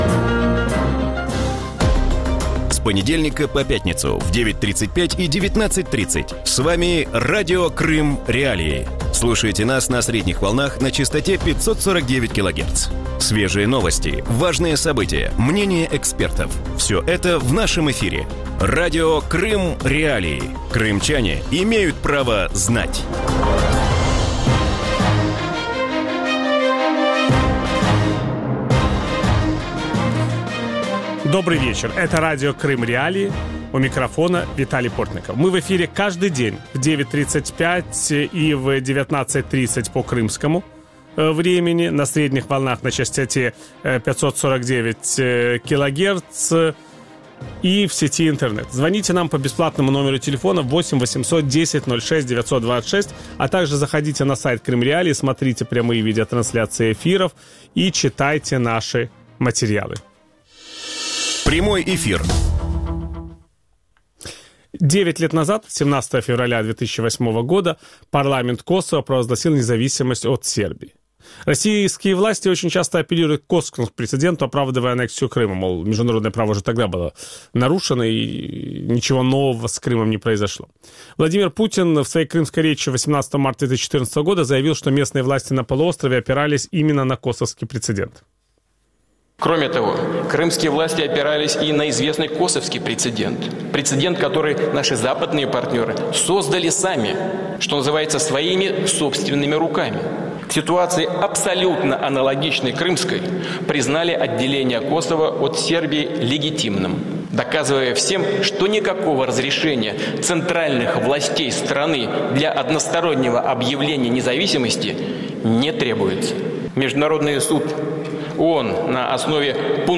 Росія погано підготувала аргументацію з виправдання анексії Криму для міжнародної спільноти. Таку думку у вечірньому ефірі Радіо Крим.Реалії висловила журналіст